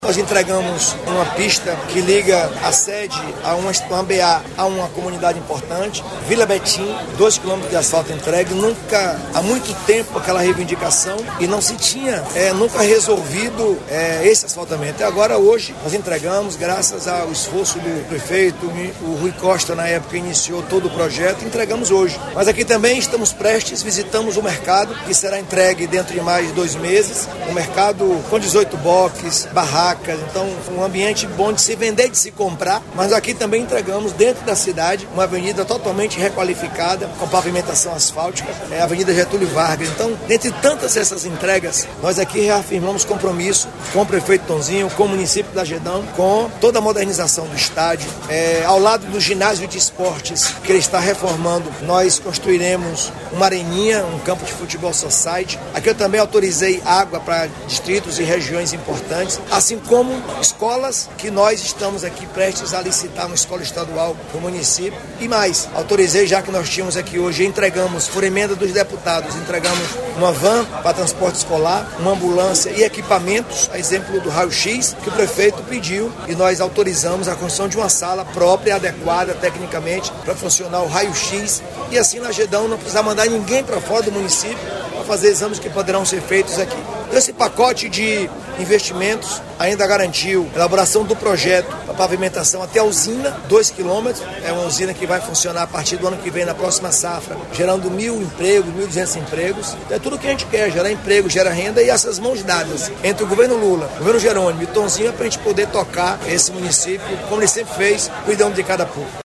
🎙Jerônimo Rodrigues – Governador da Bahia